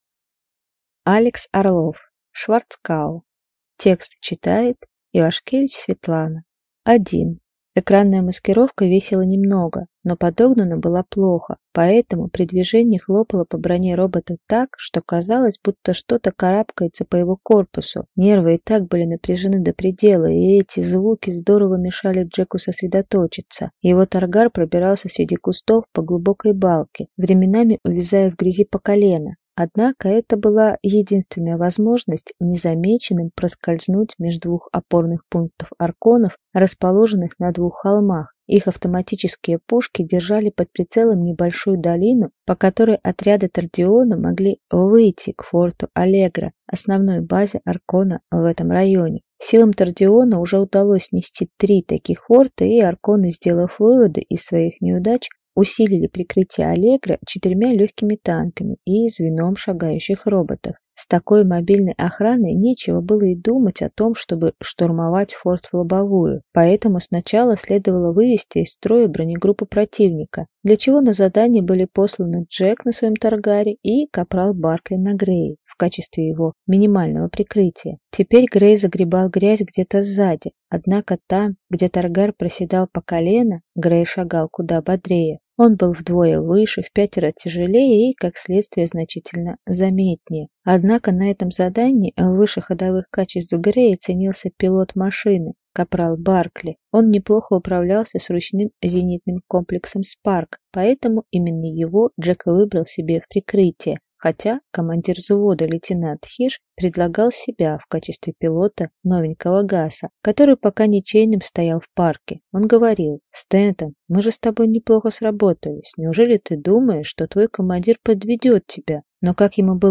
Аудиокнига Шварцкау | Библиотека аудиокниг